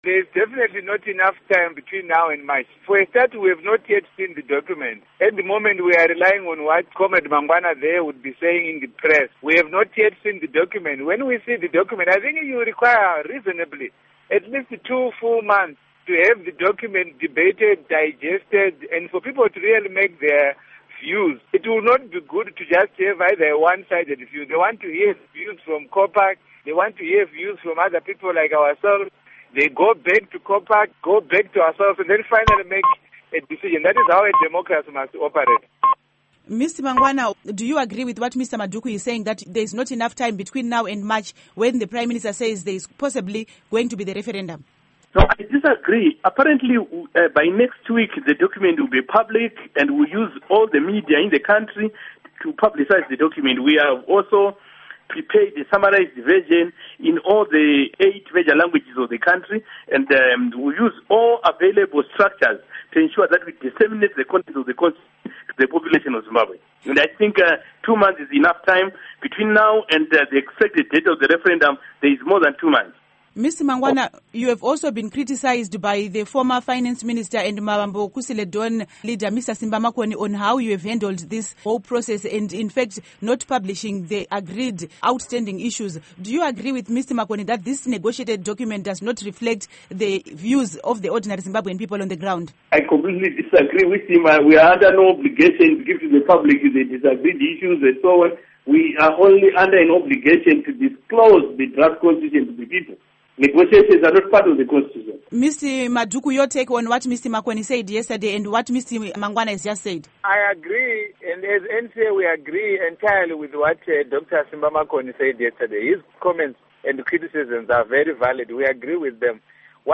Interview With Lovemore Madhuku And Paul Mangwana